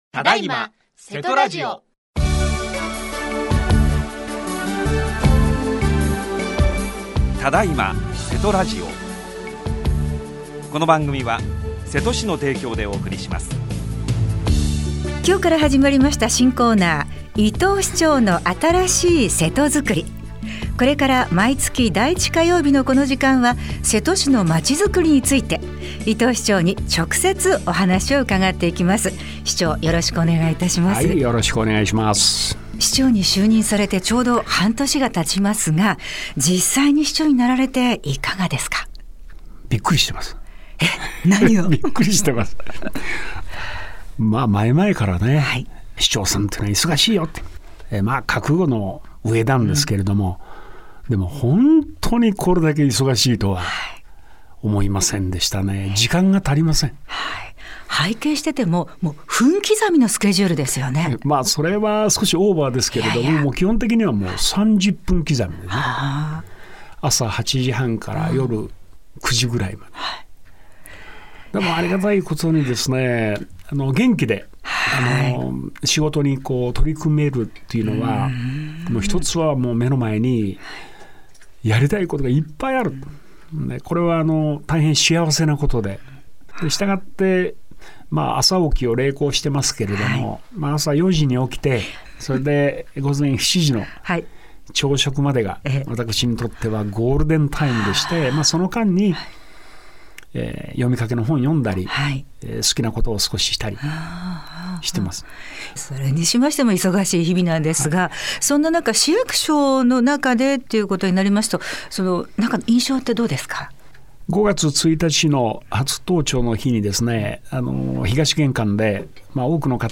27年11月3日（火） 只今せとラジオ 毎月第一週目の火曜日は 「伊藤市長の新しいせとづくり」です。 伊藤市長をお迎えしてお話を伺ってまいります。